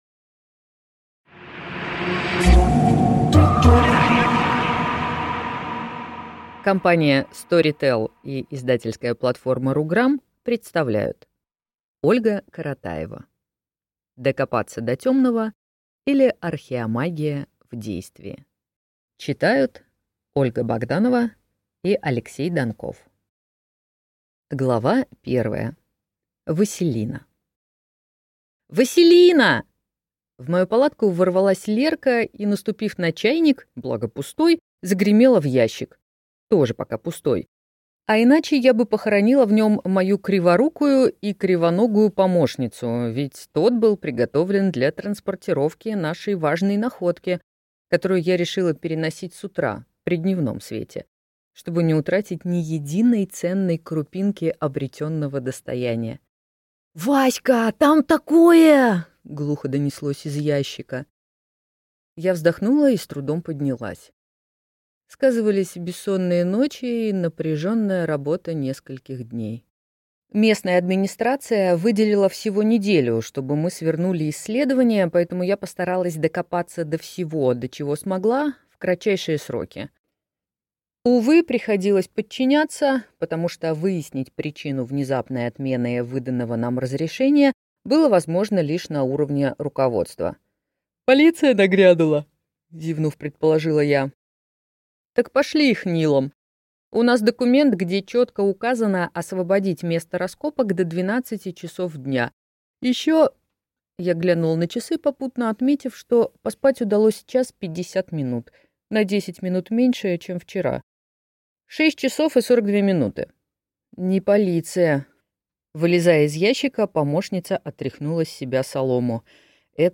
Аудиокнига Докопаться до темного, или Архиомагия в действии | Библиотека аудиокниг